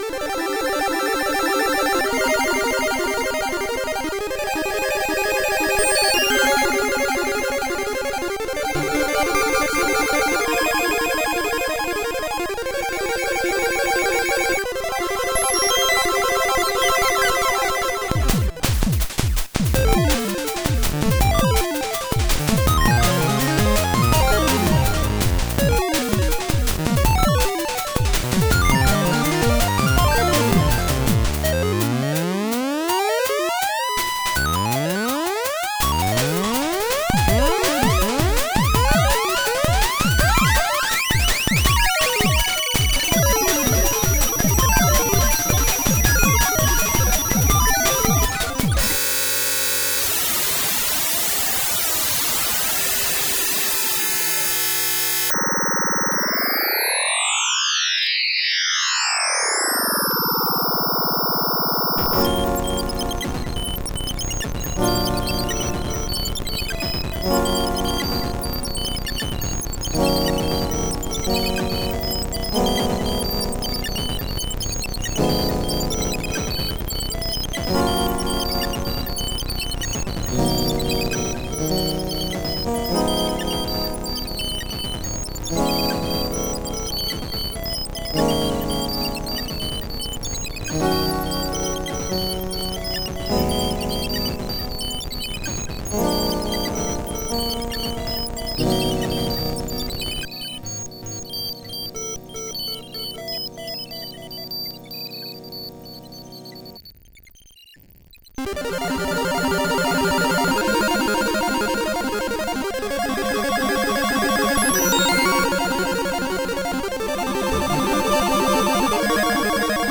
Gamma-Ray Burst --Betelgeuse-- MMLで記述した、ネイティブ"Chiptune"の音楽ファイルです。